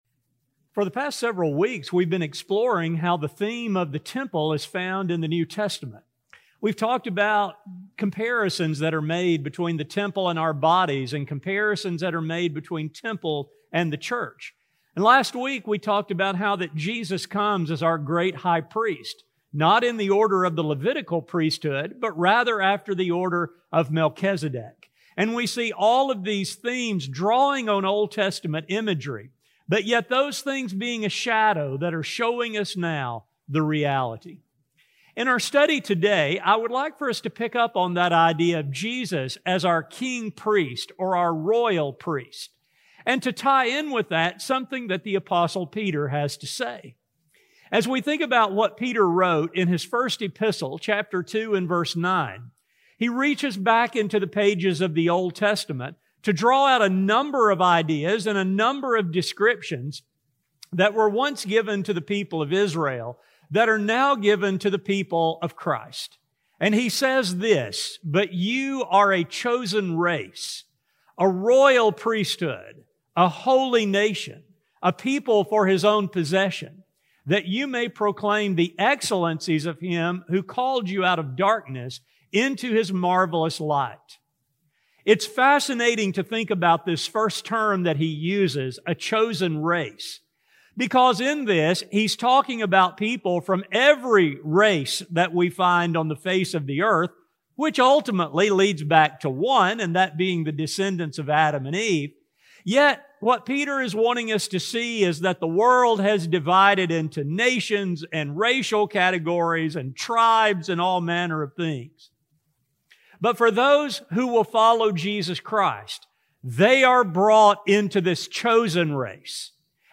A sermon recording